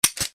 EmptyClip_B.mp3